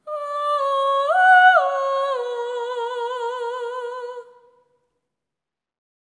ETHEREAL11-L.wav